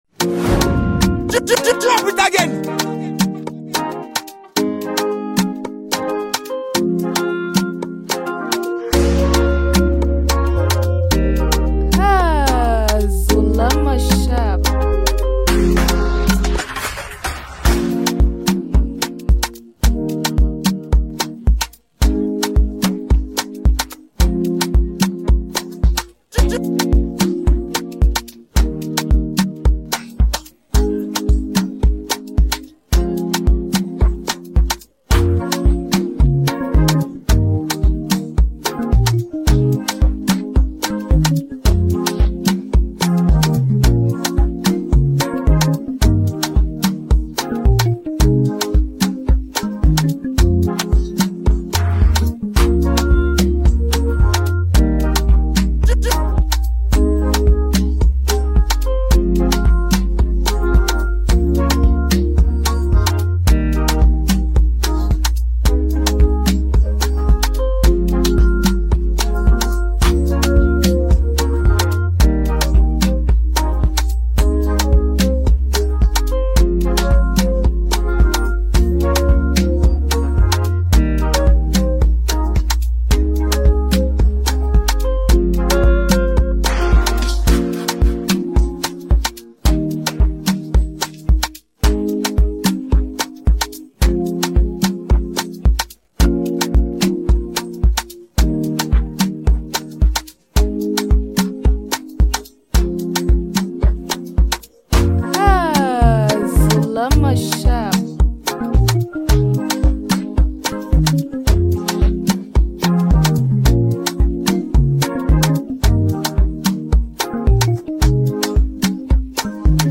Afro dancehall Afrobeats